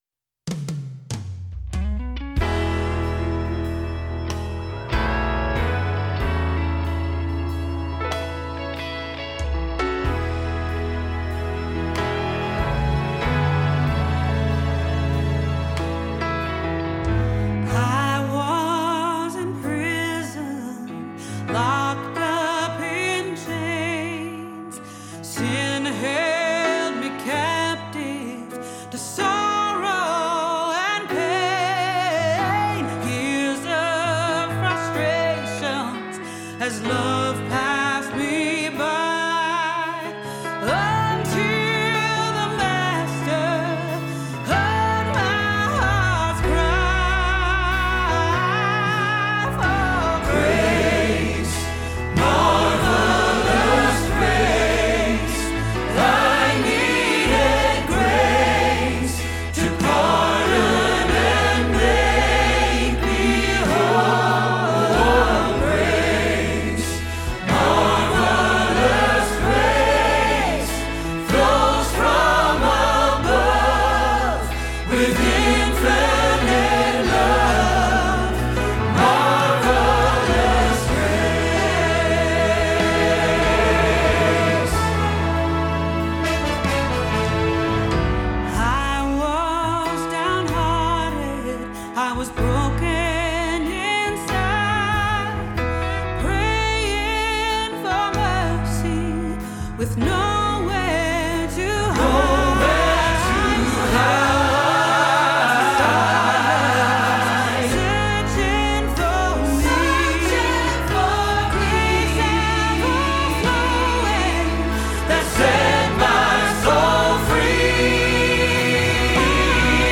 02-Grace-with-Grace-Greater-Than-Our-Sin-Tenor-Rehearsal-Track.mp3